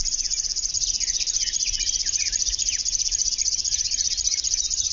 Сверчки
Стрекотание сверчков